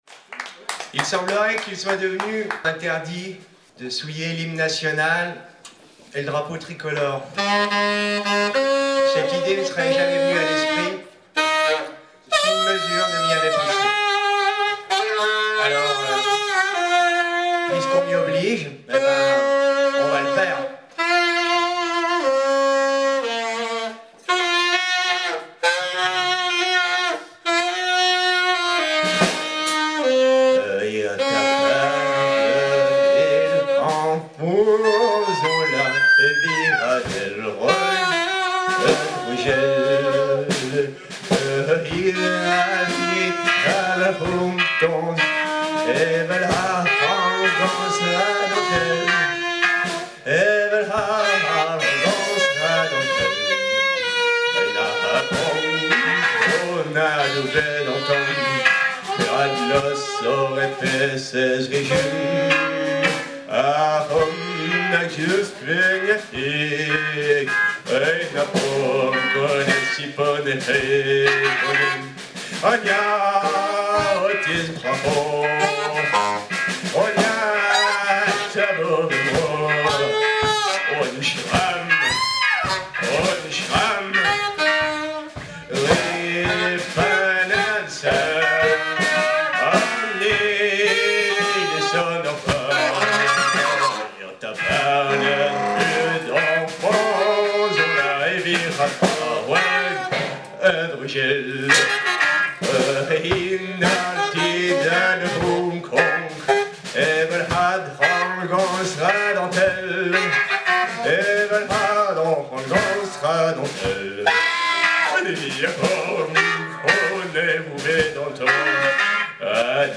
Concert de soutien